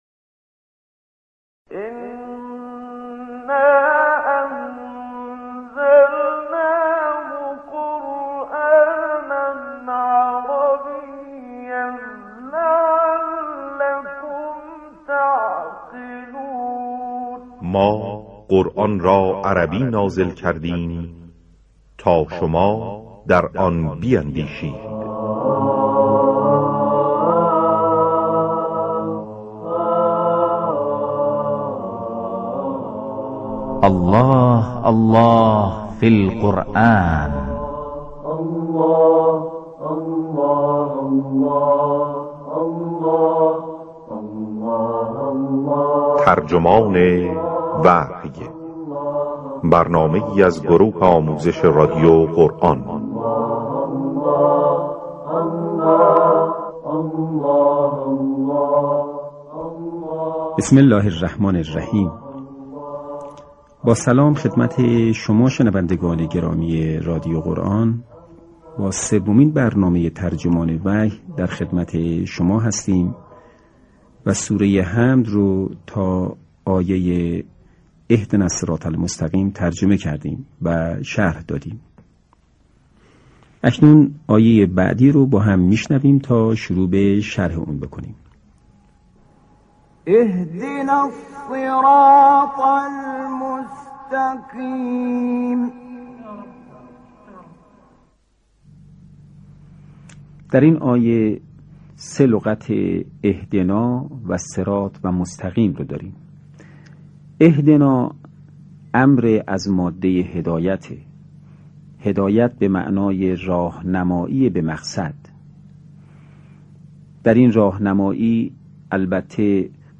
این مجموعه (ترجمان وحی)، طی سال‌های 1382 تا 1390 از رادیو قرآن، پخش گردید.